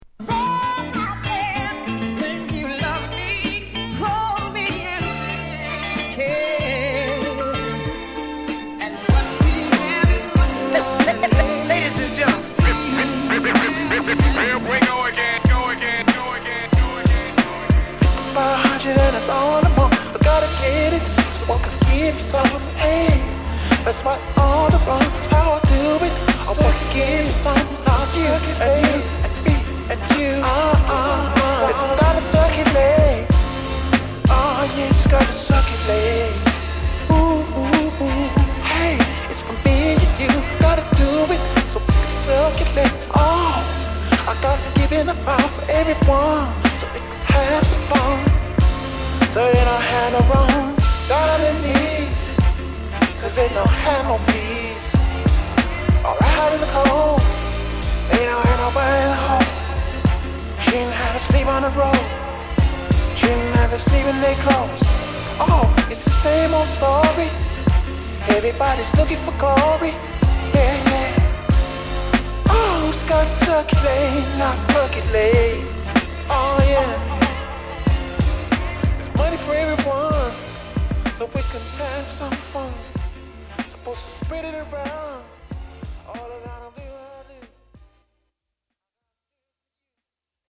どちらも文句無しのスムースでナイスなブレンド！！